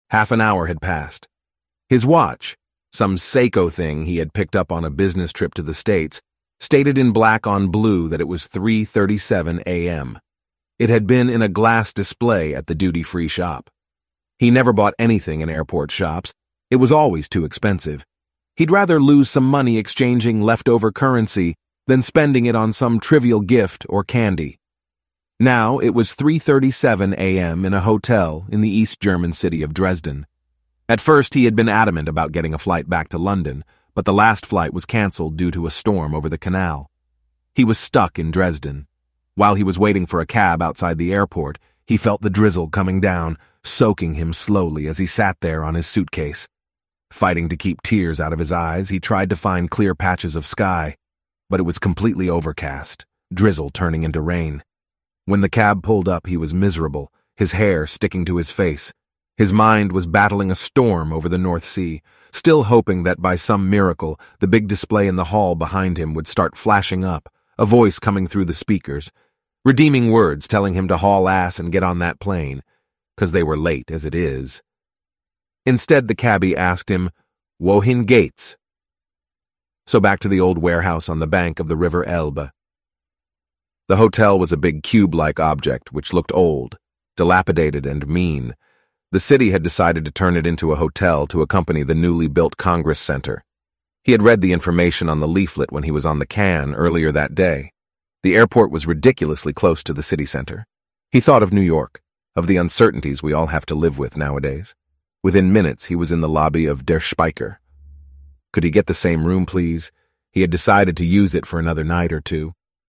Both of these forms of writing I could publish on my website, but a couple of months (maybe a year?) ago, I toyed with Elevenlabs AI voice generation and I was really very, very pleasantly surprised by how entertaining and enjoyable it was to hear a snippet of an -admittedly- very old unfinished story being read aloud, as if by a professional voice actor.